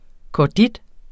Udtale [ kɒˈdid ]